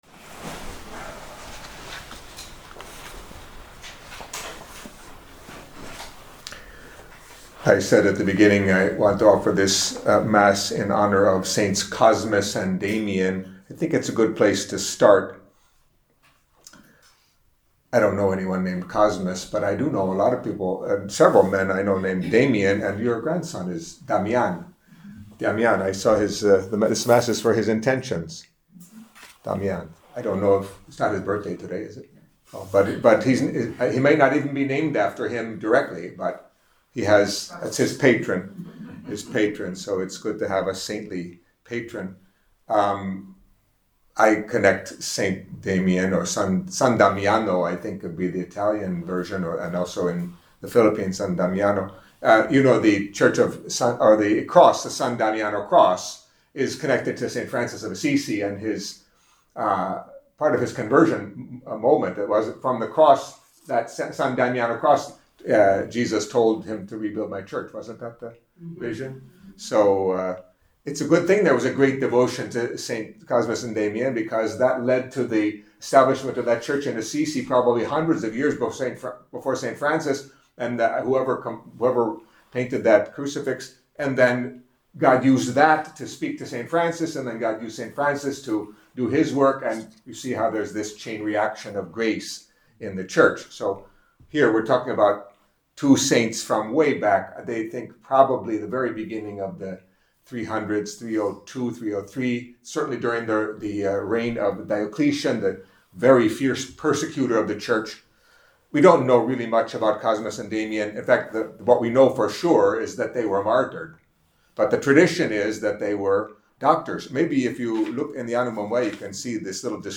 Catholic Mass homily for Friday of the Twenty-Fifth Week in Ordinary Time